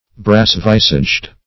Brass-visaged \Brass"-vis"aged\